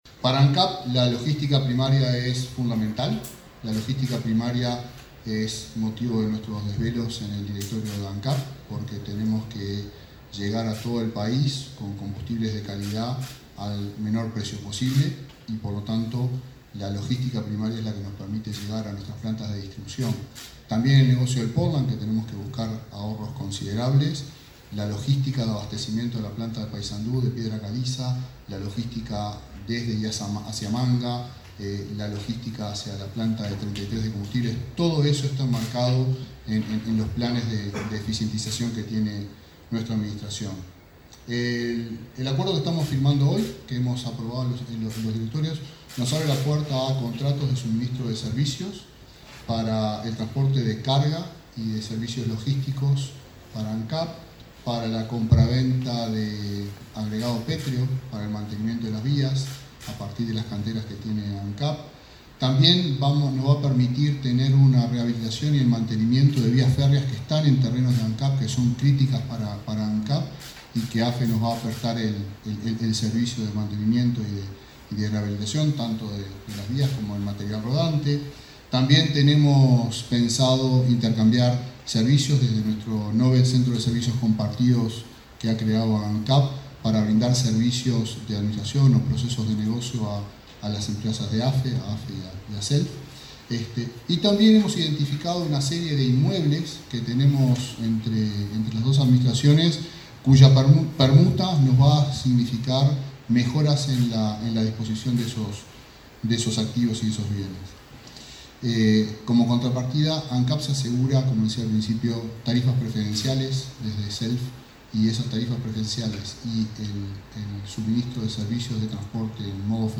Conferencia de prensa de autoridades de Ancap y AFE
Este martes 29, el presidente de Ancap, Alejandro Stipanicic, y el titular de AFE, Miguel Vaczy, anunciaron, en una conferencia de prensa, un acuerdo institucional que propiciará el intercambio de servicios logísticos, materiales e inmuebles entre ambas empresas. Acompañaron a los jerarcas los vicepresidentes de Ancap, Diego Durand, y de AFE, Gustavo Osta.